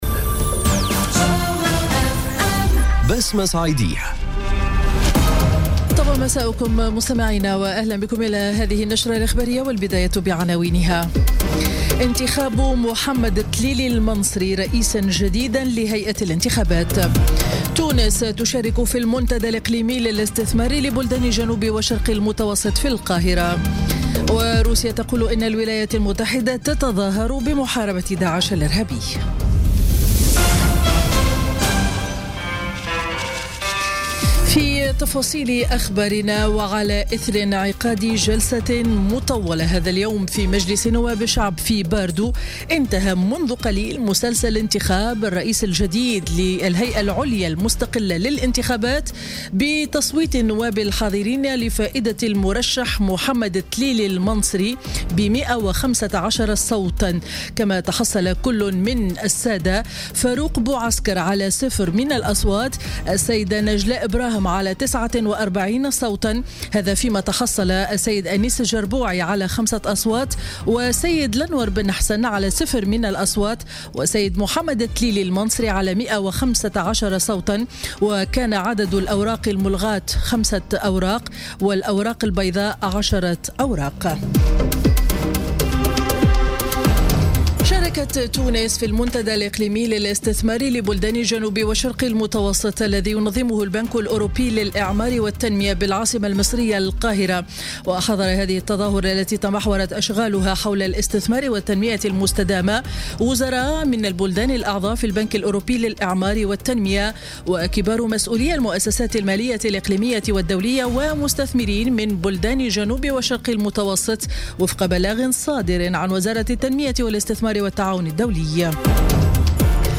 نشرة أخبار السابعة مساء ليوم الثلاثاء 14 نوفمبر 2017